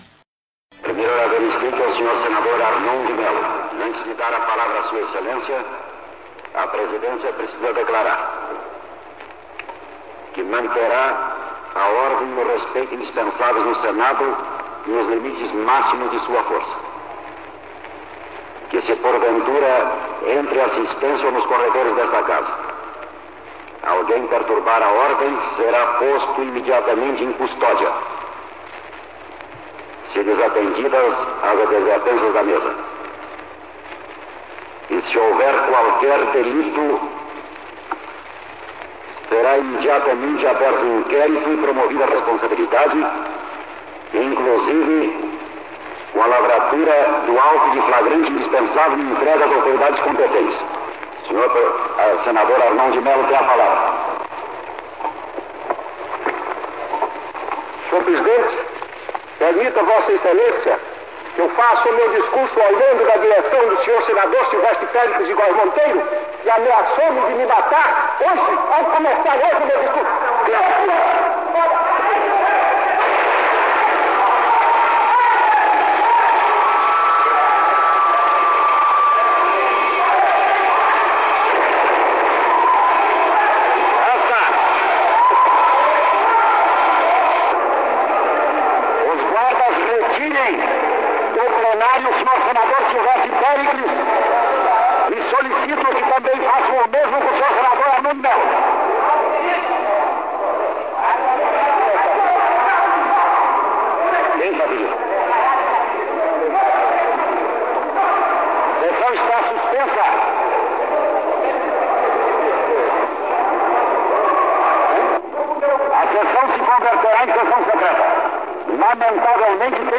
(Estampidos. Tumulto)
Áudio da sessão em que Arnon de Melo (PDC AL) discute com Silvestre Péricles (PST – AL) e dispara contra ele.